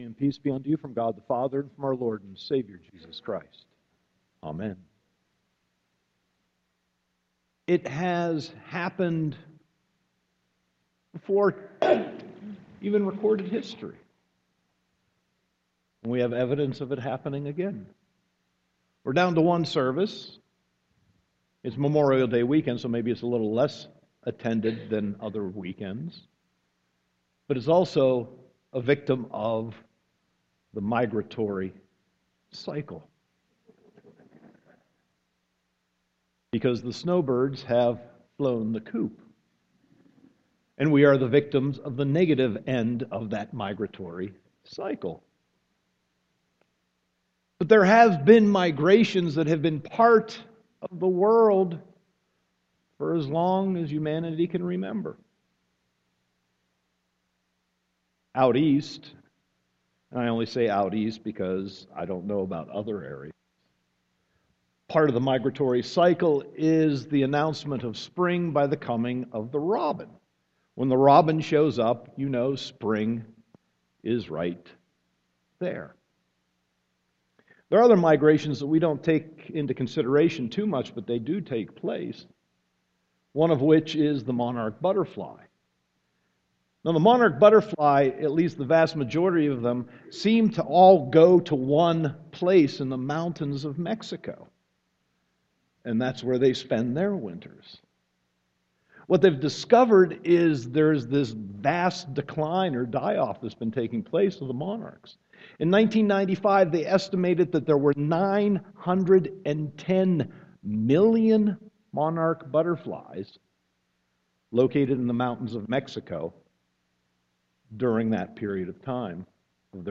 Sermon 5.24.2015